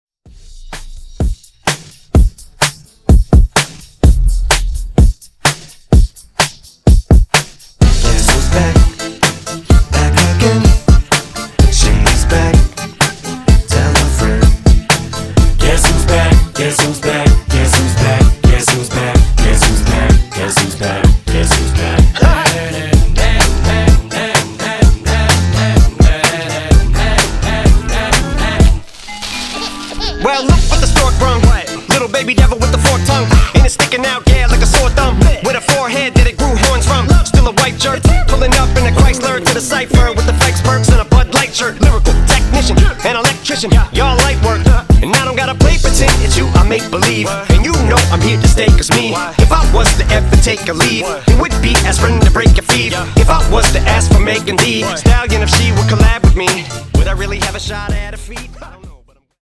Genres: 2000's , RE-DRUM
Clean BPM: 125 Time